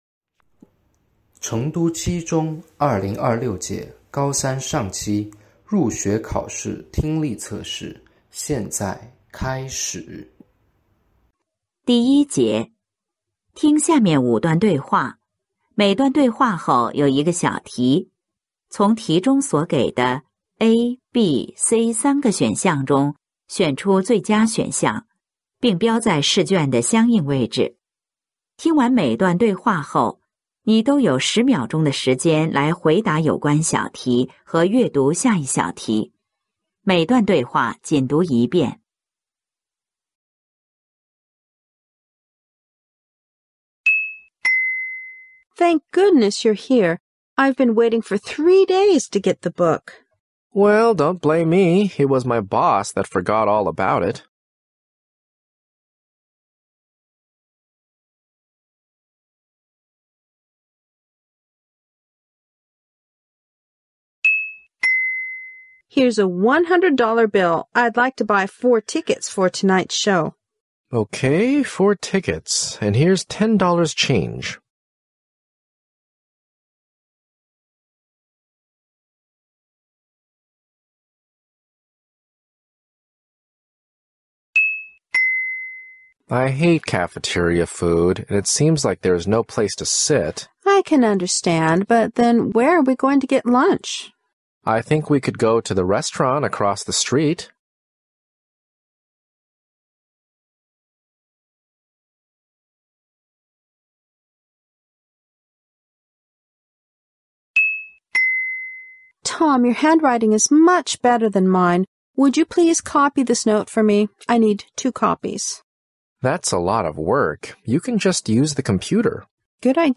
成都七中2025-2026学年高三上学期8月入学考试英语听力.mp3